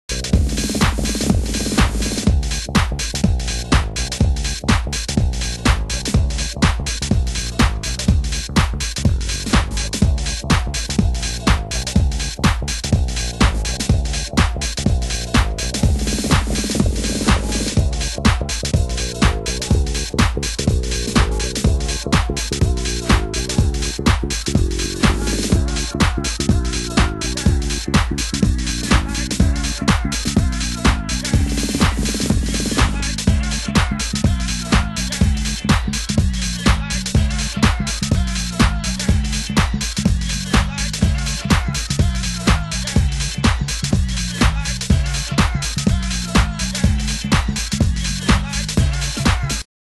ドラムロール、サンプルのループ、フィルターの具合などが、フロアの享楽感を満たす仕上がり！